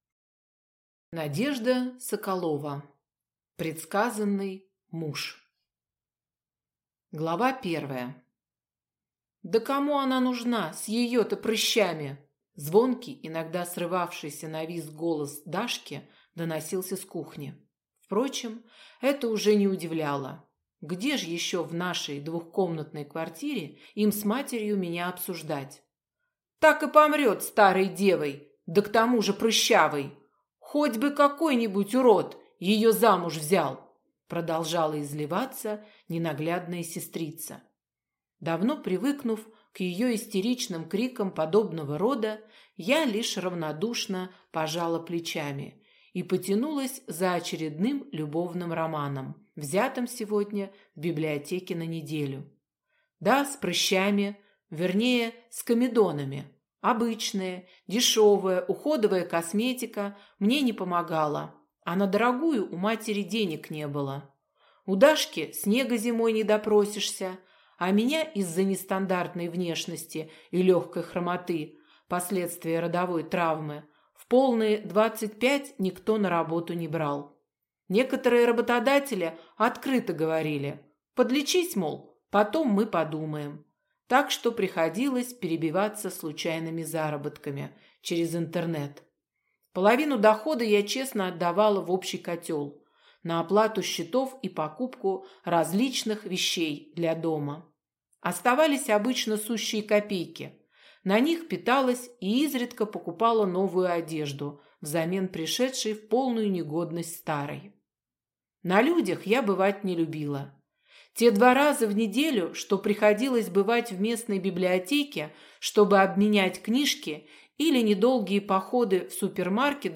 Слушать аудиокнигу Волк 8